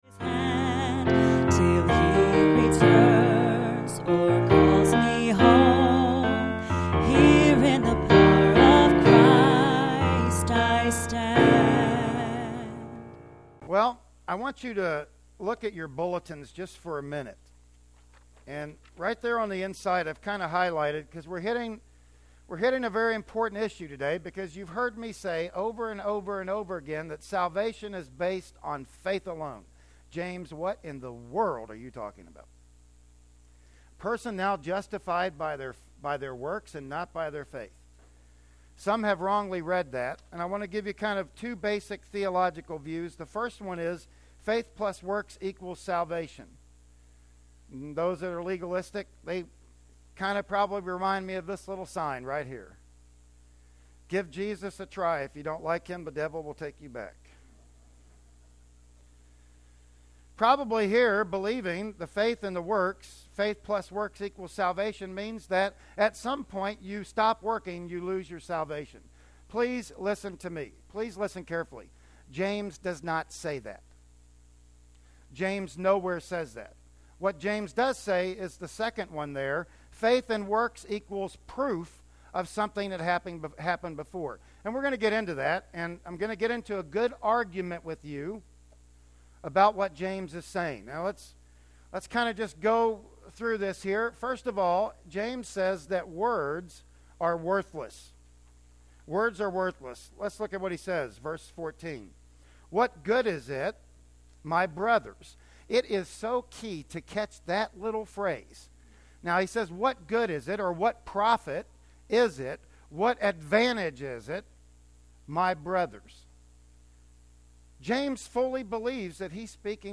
"James 2:14-26" Service Type: Sunday Morning Worship Service Bible Text